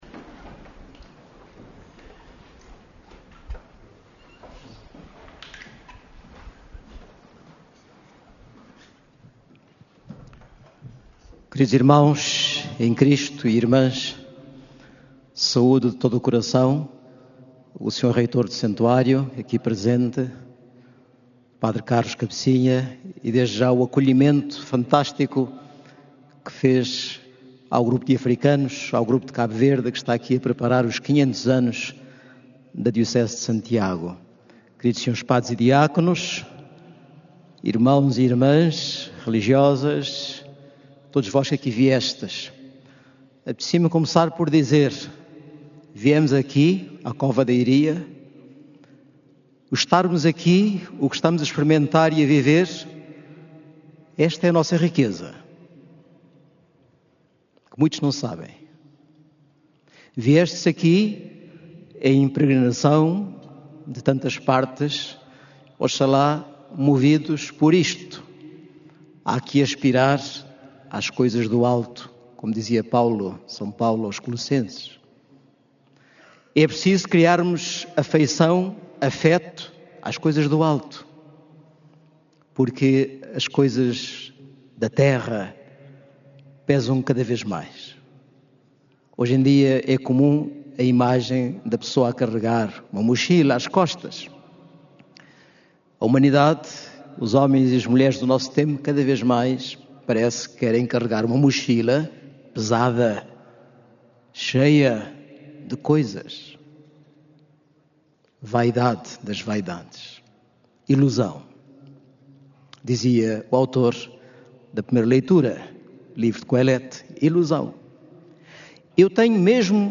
“Há uma corrida desenfreada ao ter, ao possuir, há uma autêntica idolatria do consumismo nos nossos dias e, contudo, nem por isso somos mais felizes”, afirmou D. Ildo Fortes, bispo do Mindelo, Cabo Verde, na homilia que proferiu esta manhã, na missa celebrada no Recinto de Oração do Santuário de Fátima.
Áudio da homilia de D. Ildo Fortes
homilia-d.-ildo-fortes.mp3